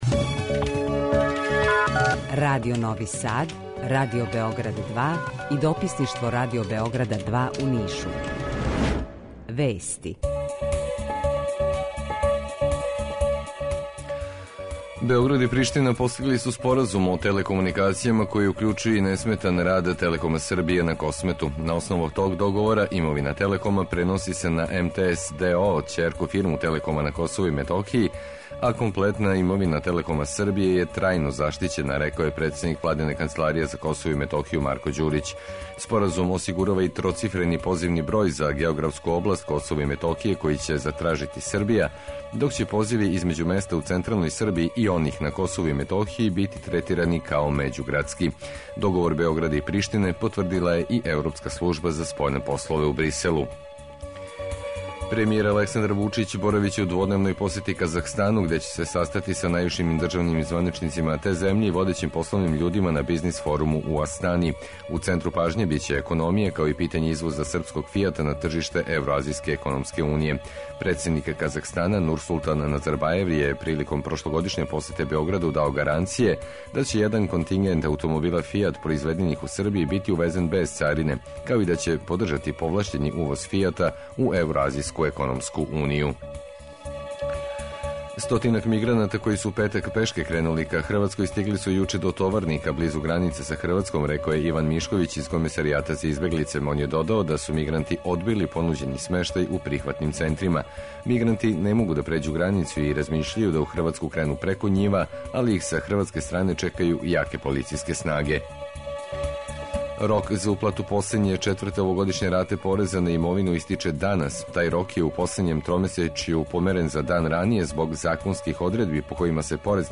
Jутарњи програм заједнички реализују Радио Београд 2, Радио Нови Сад и дописништво Радио Београда из Ниша.
У два сата ту је и добра музика, другачија у односу на остале радио-станице.